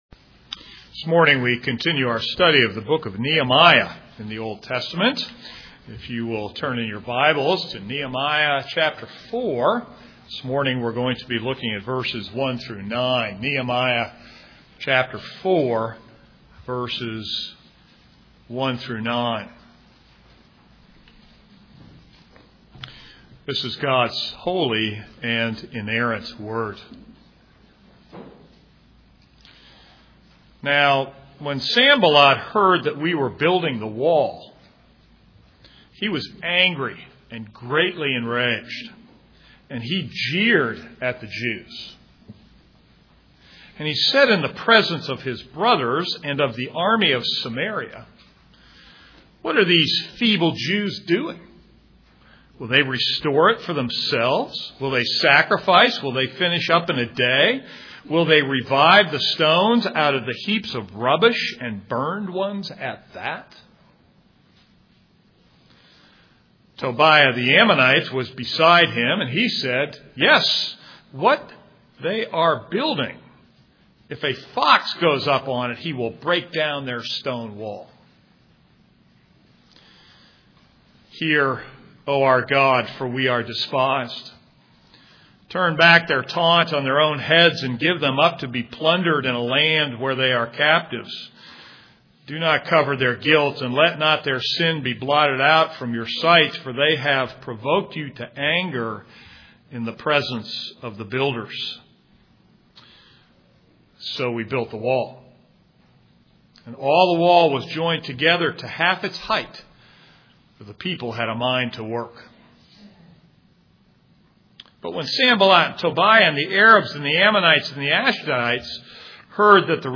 This is a sermon on Nehemiah 4:1-9.